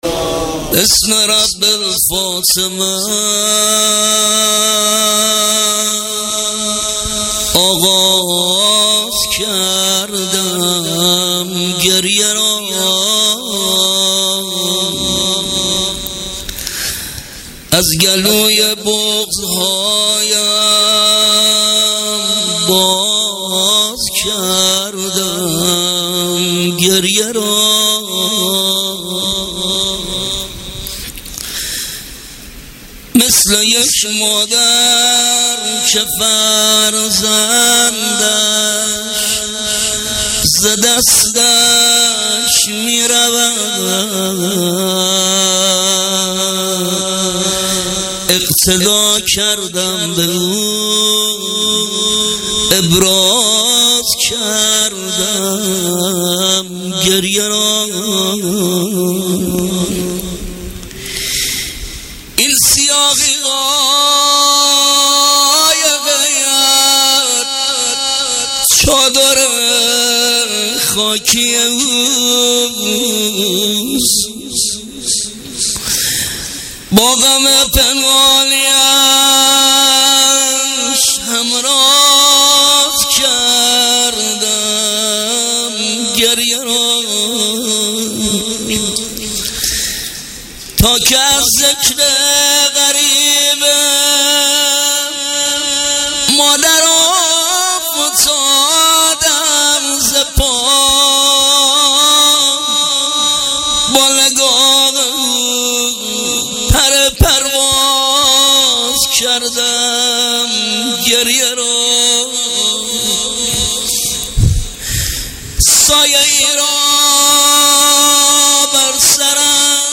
مراسم استقبال از ماه محرم ۹۸